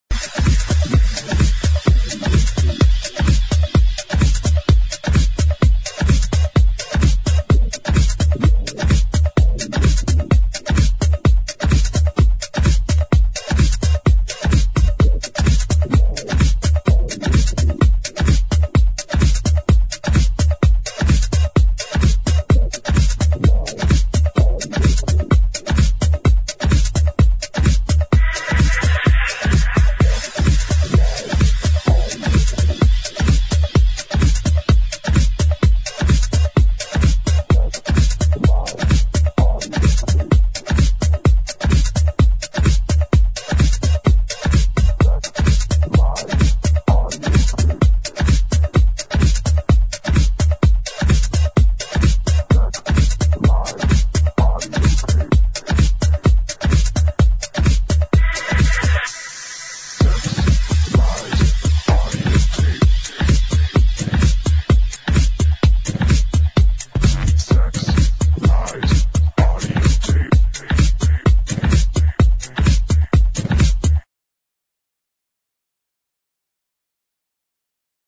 HOUSE / TECH HOUSE